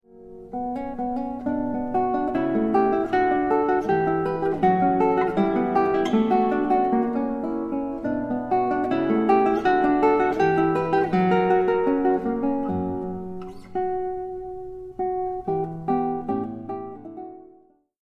mp3Barrios, Agustin, Gavota al Estilo Antiguo for guitar, pickup to mm. 34-41